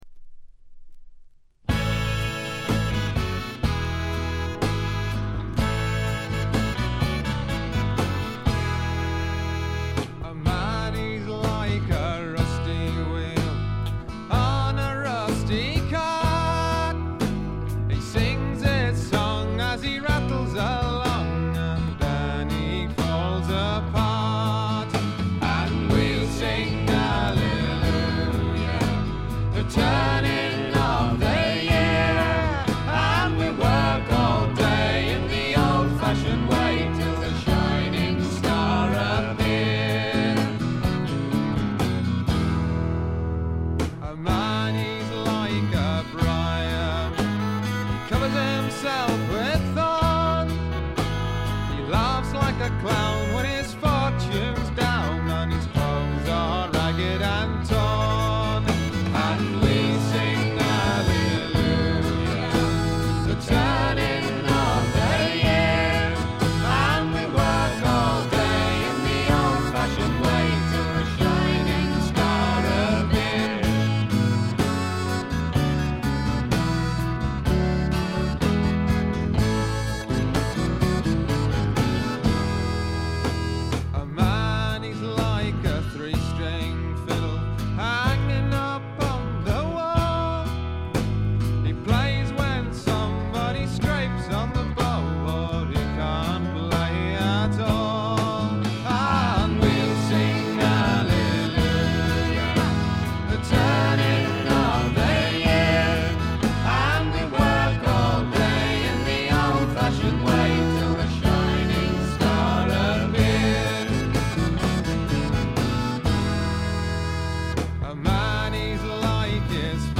B面にチリプチ少々。
英国のフォークロックはこう来なくっちゃというお手本のようなもの。
試聴曲は現品からの取り込み音源です。